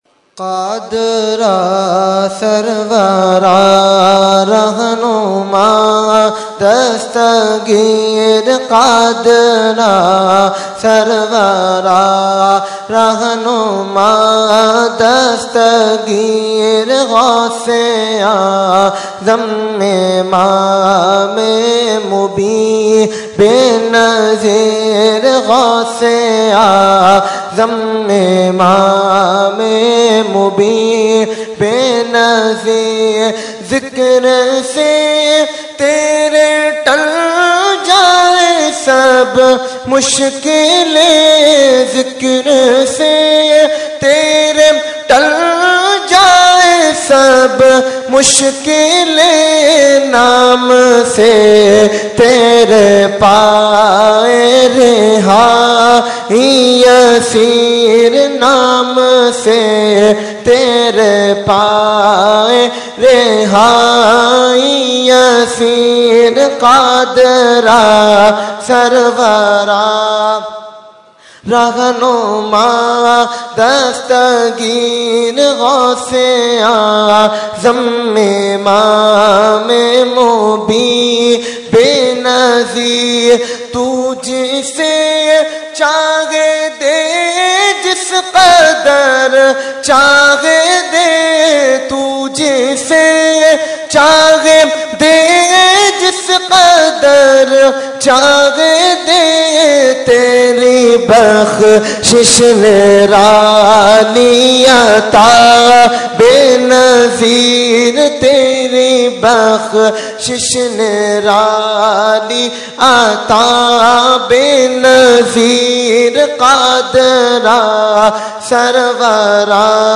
Category : Manqabat | Language : UrduEvent : 11veen Shareef 2016